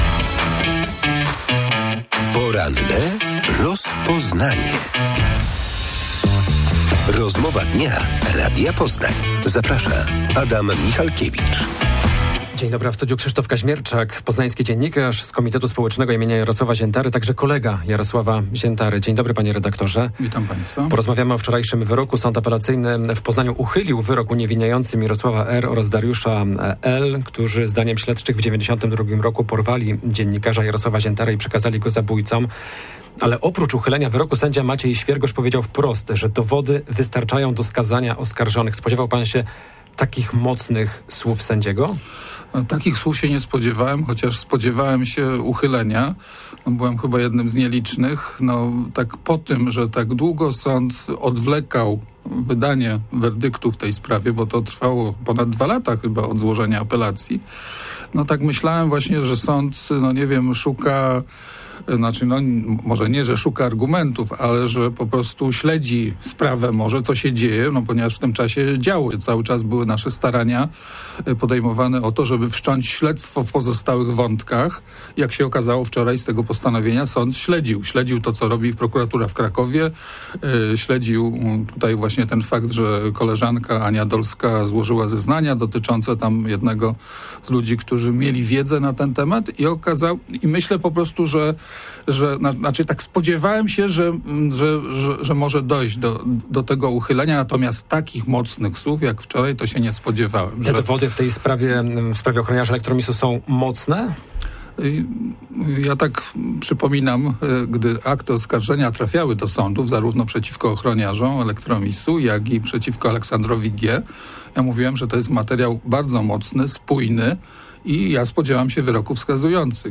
Dziś w porannej rozmowie Radia Poznań komentował wczorajszą decyzję Sądu Apelacyjnego w Poznaniu, który uchylił wyrok uniewinniający ochroniarzy Elektromisu.